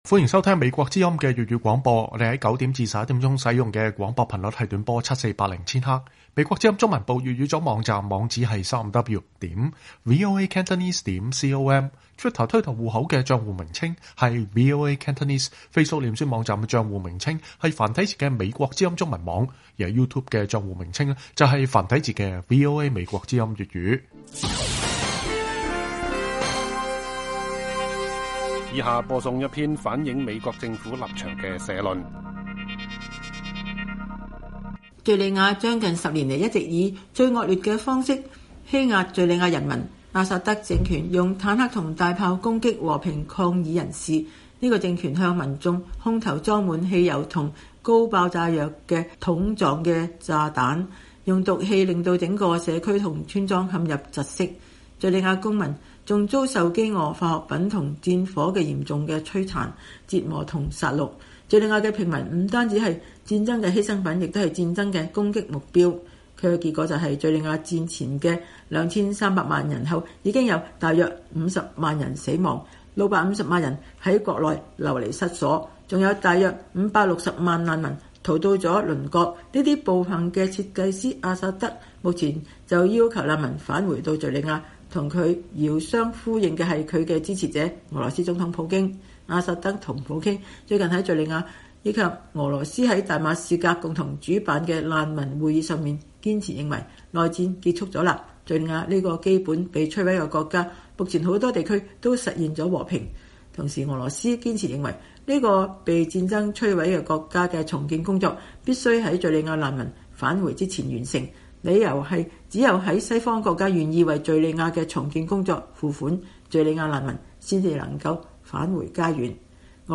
美國政府立場社論